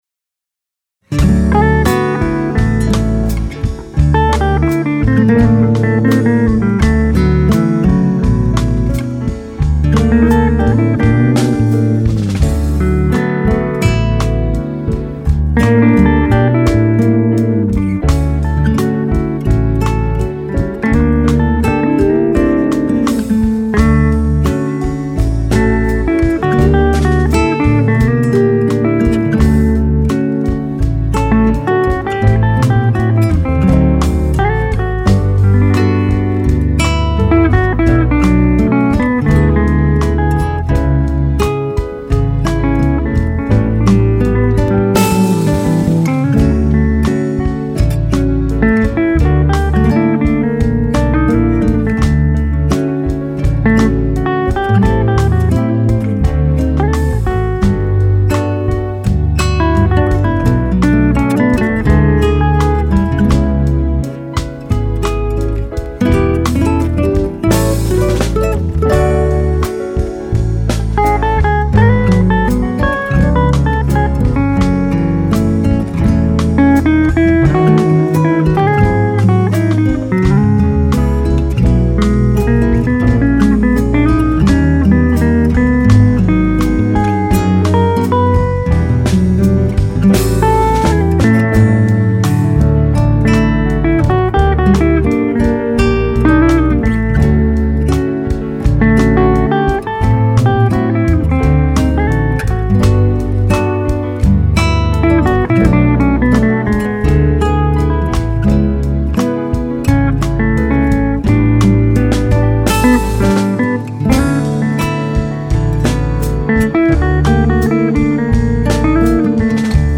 759   06:30:00   Faixa:     Bossa nova
Guitarra